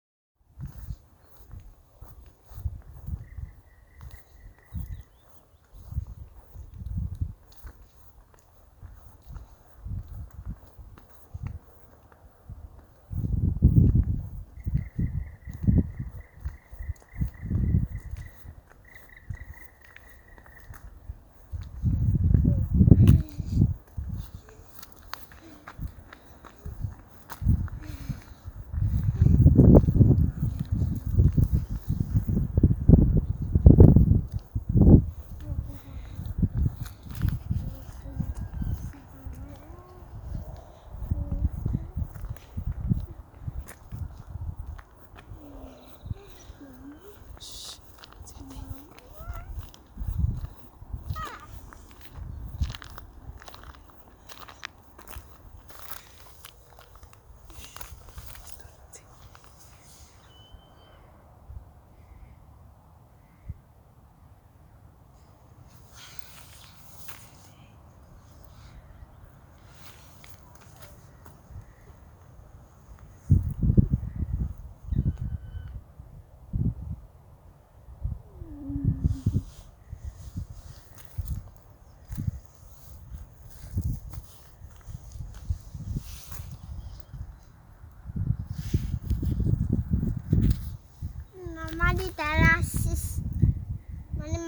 черный дятел, Dryocopus martius
СтатусСлышен голос, крики